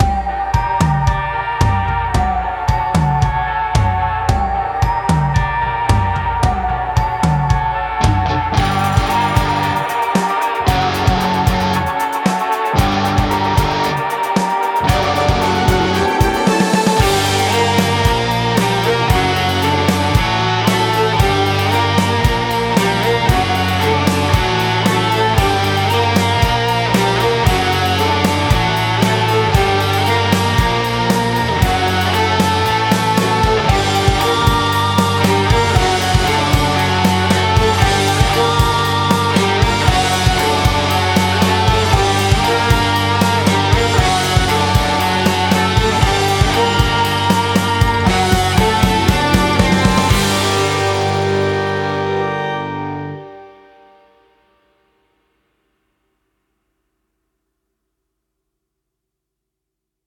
Genre: metal, rock.